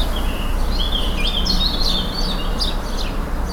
birds singing in garden 3.wav
Blackbirds singing in a city garden with a Tascam DR05.
.WAV .MP3 .OGG 0:00 / 0:04 Type Wav Duration 0:04 Size 608,27 KB Samplerate 44100 Hz Bitdepth 1411 kbps Channels Stereo Blackbirds singing in a city garden with a Tascam DR05.
birds_singing_in_garden_3_c24_hez.ogg